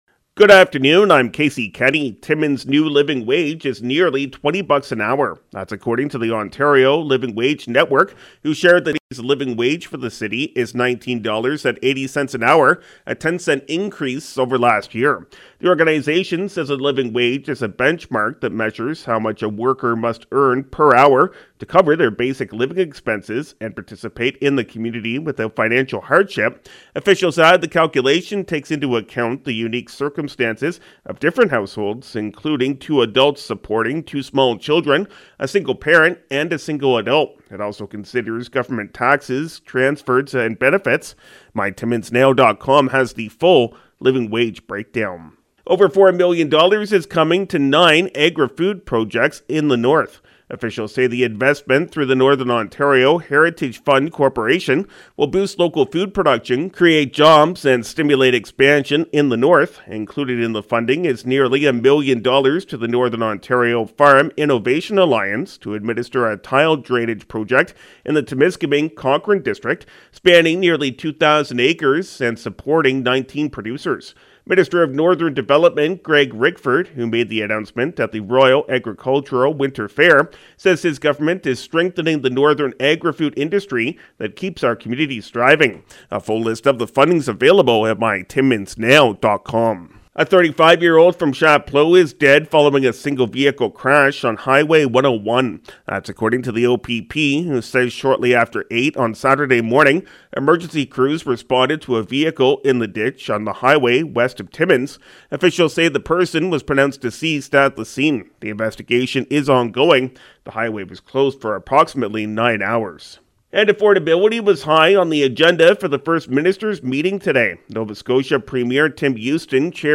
5:00pm Country 93.1 News – Mon., Nov. 06, 2023